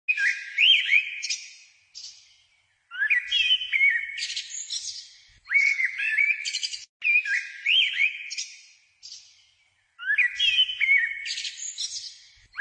编织大师黄胸织布鸟叫声